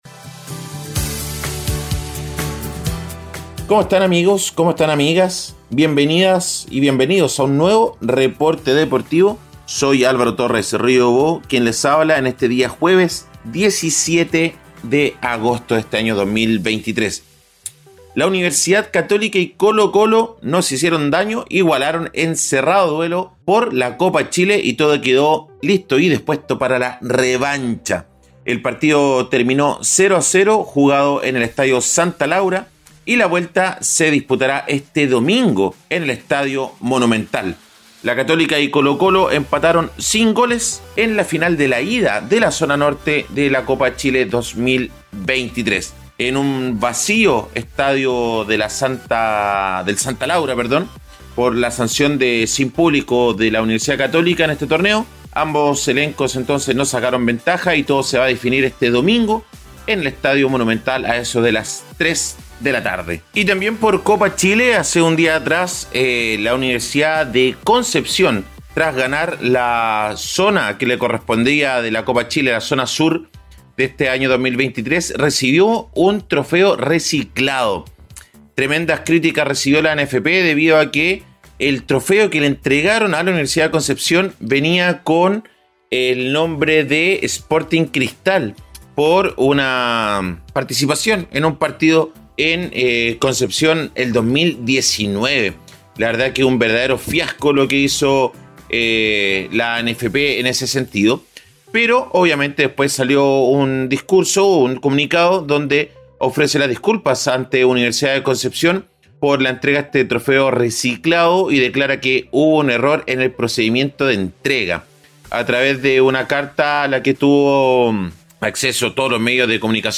Reporte Deportivo 🎙 Podcast 17 de agosto de 2023